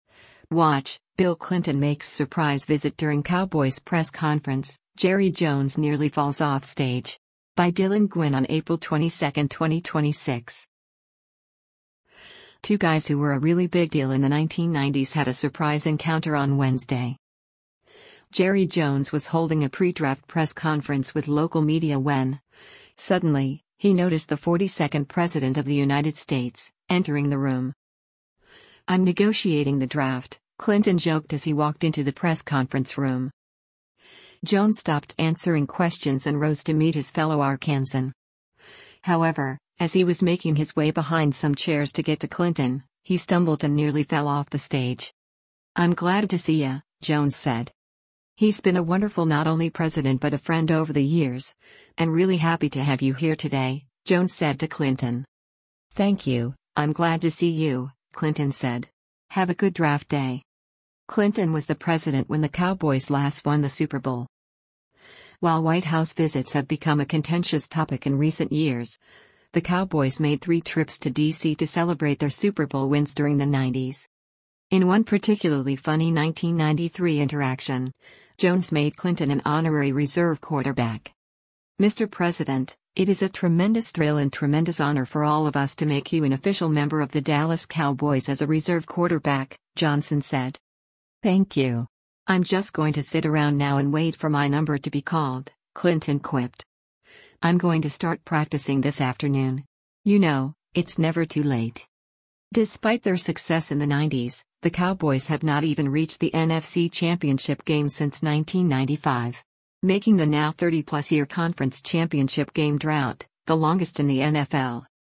Jerry Jones was holding a pre-draft press conference with local media when, suddenly, he noticed the 42nd President of the United States, entering the room.
“I’m negotiating the draft,” Clinton joked as he walked into the press conference room.